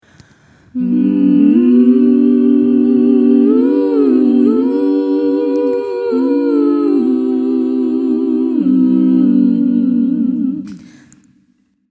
We kunnen ook enige melodie in de oefening aanbrengen.
Begin op een g' (zoals op de afbeelding) en ga dan steeds een halve toon lager tot de d' en daarna start je weer op de g' en ga steeds dan halve toon omhoog tot de d’ (of hoger indien het lekker in je stem voelt.)